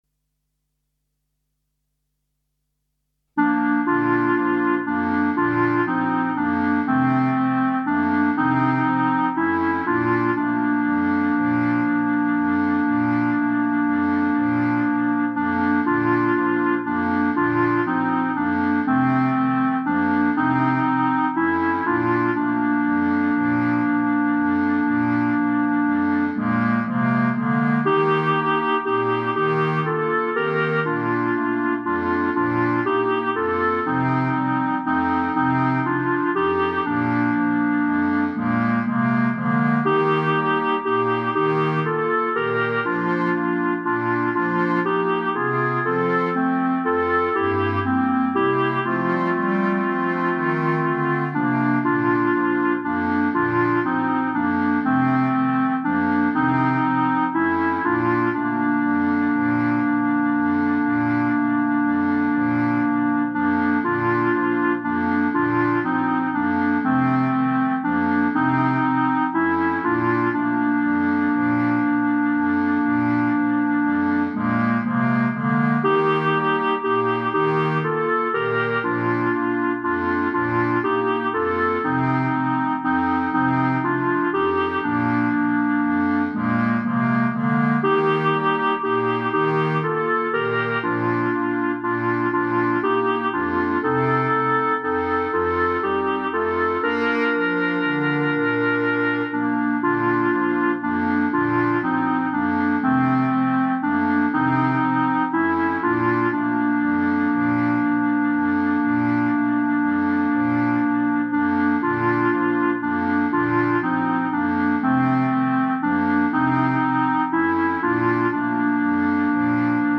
Instrumentation:4 Clarinet + Bass Cl.
An easy arrangement of this beautiful Neapolitan song.
Minimum Forces melody + bass.